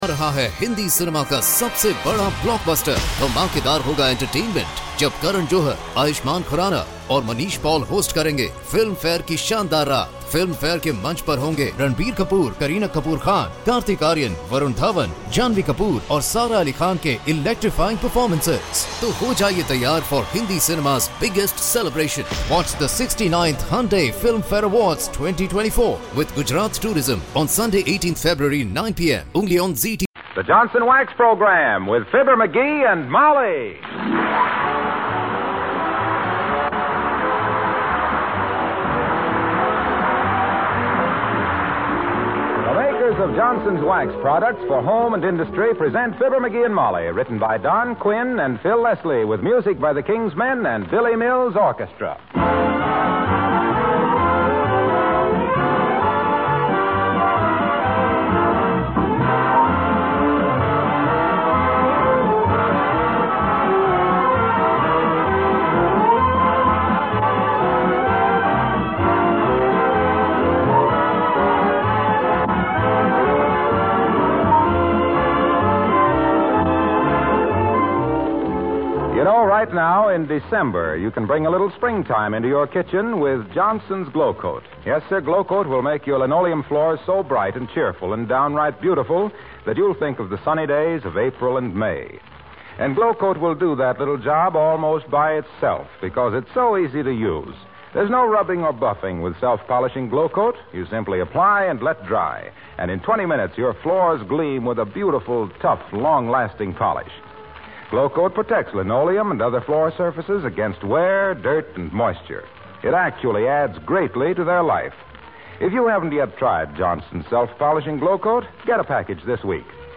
Fibber McGee and Molly was an American radio comedy series.
The title characters were created and portrayed by Jim and Marian Jordan, a real-life husband and wife team that had been working in radio since